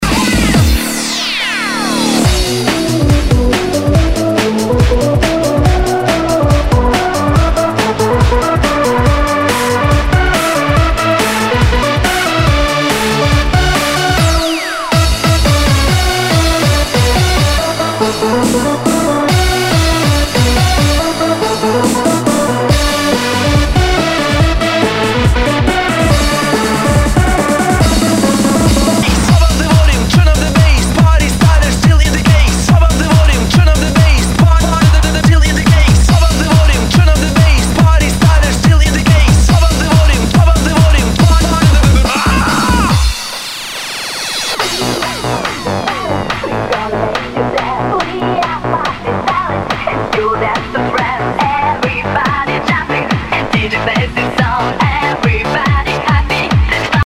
HOUSE/TECHNO/ELECTRO
ナイス！ユーロ・ハード・ハウス！